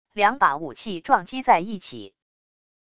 两把武器撞击在一起wav音频